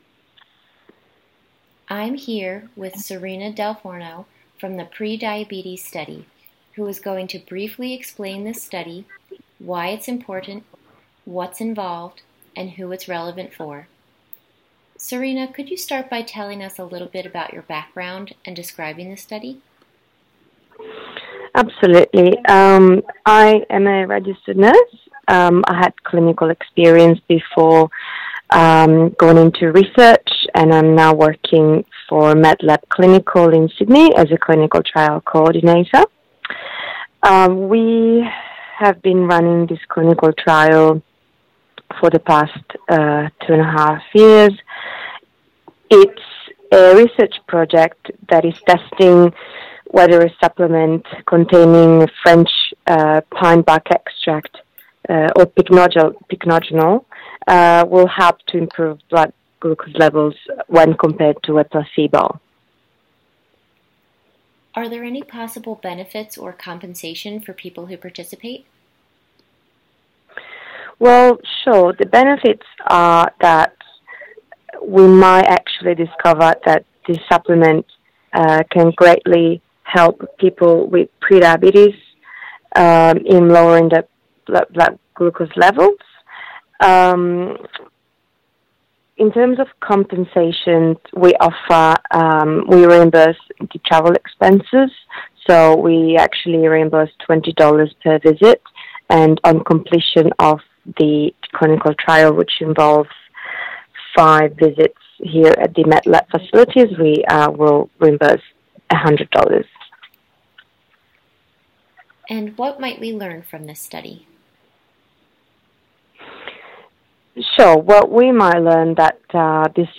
Researcher interview: